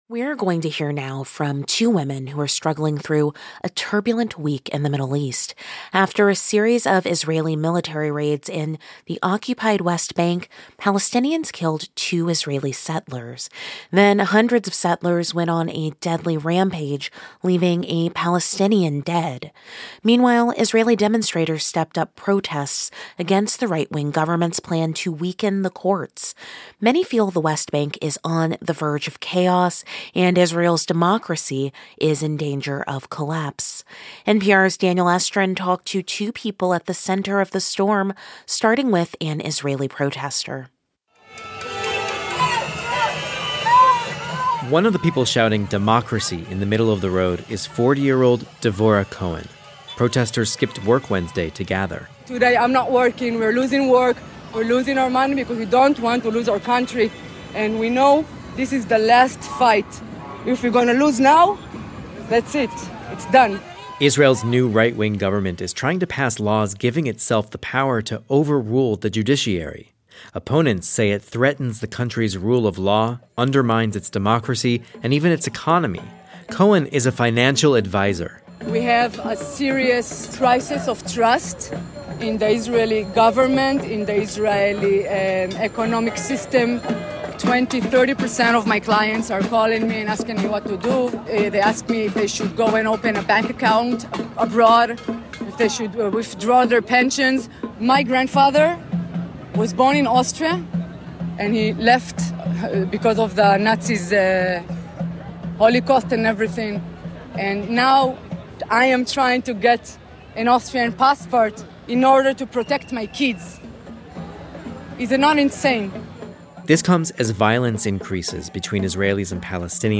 We hear from an Israeli and a Palestinian at the heart of the recent turmoil in Israel and the West Bank.